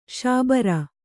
♪ śabara